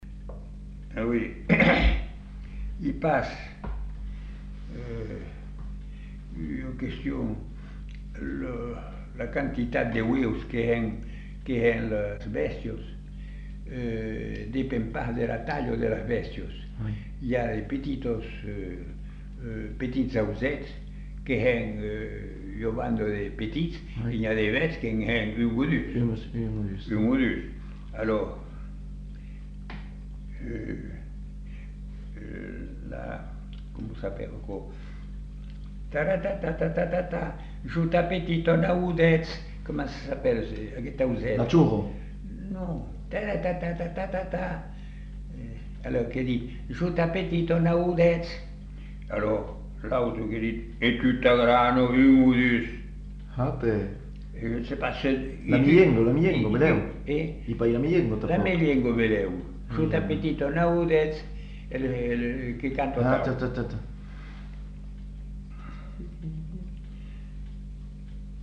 Lieu : Masseube
Genre : forme brève
Effectif : 1
Type de voix : voix d'homme
Production du son : récité
Classification : mimologisme